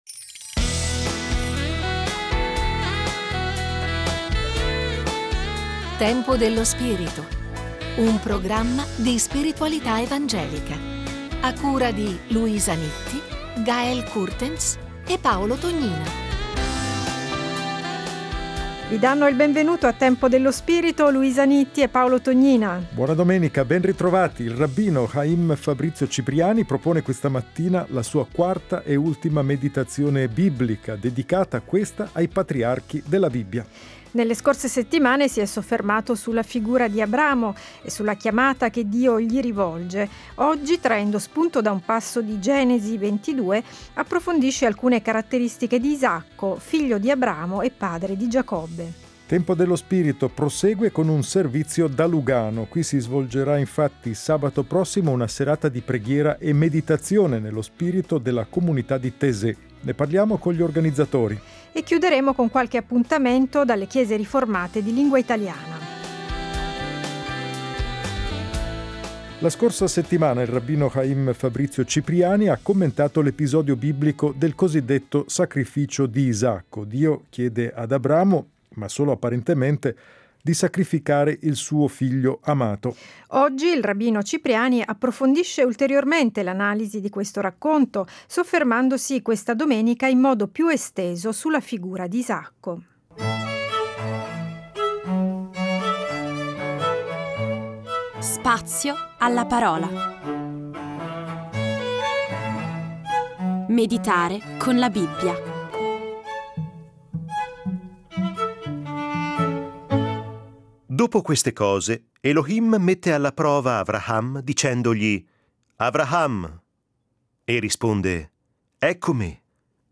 Scopri la serie Tempo dello spirito Settimanale di spiritualità evangelica.